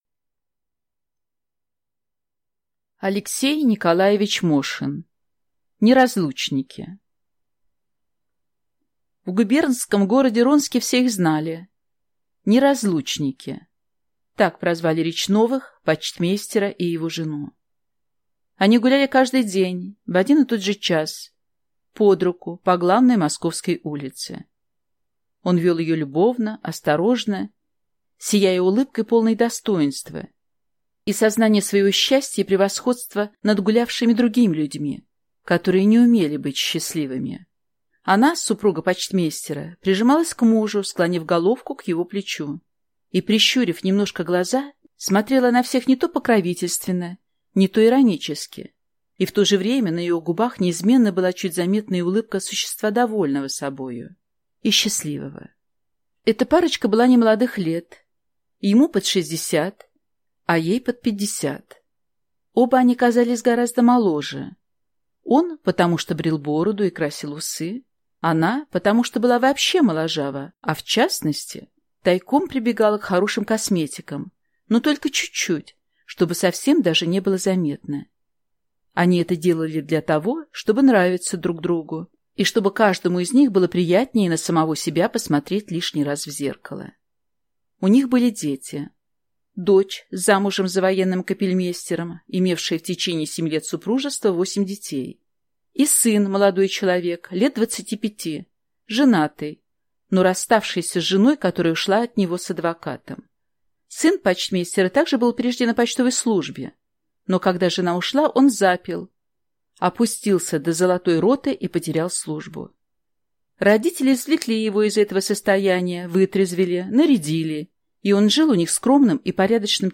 Аудиокнига Неразлучники | Библиотека аудиокниг